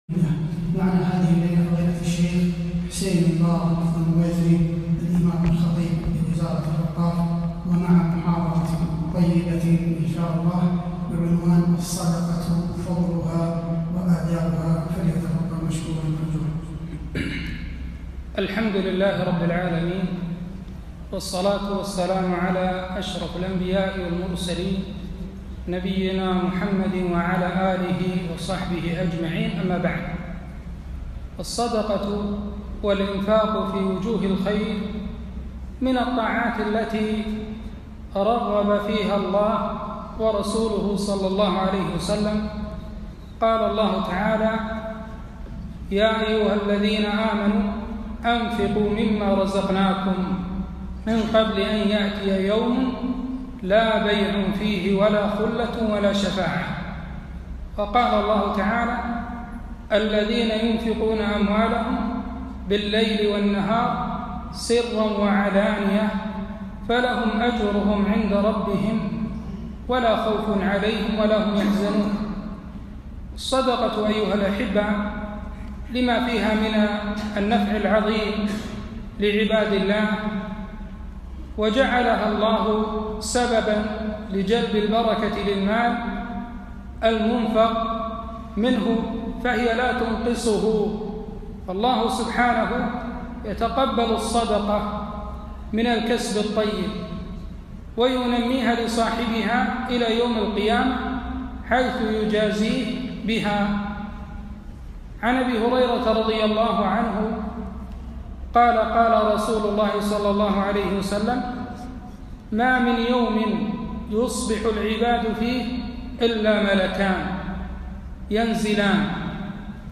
محاضرة - الصدقة فضائل وآداب